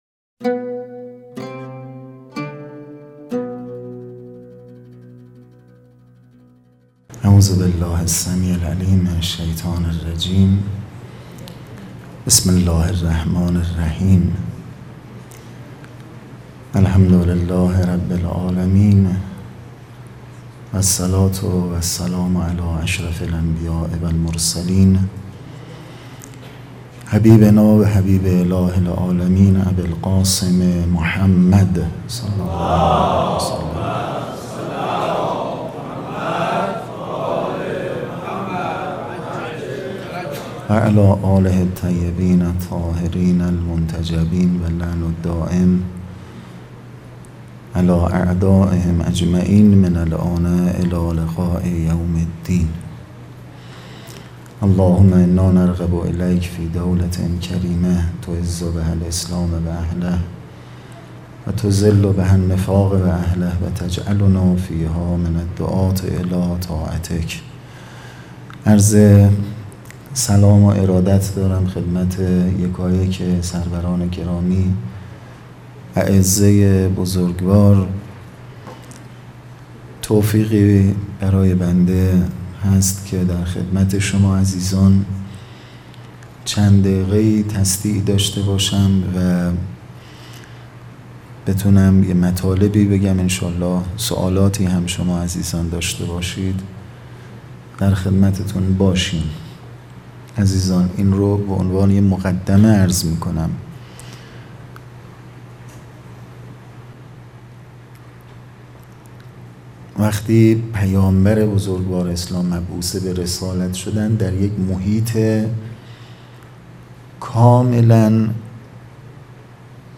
سخرانی حجت الاسلام پژمان فر | ششمین همایش هیأت‌های محوری و برگزیده کشور | شهر مقدس قم - مجتمع یاوران مهدی (عج)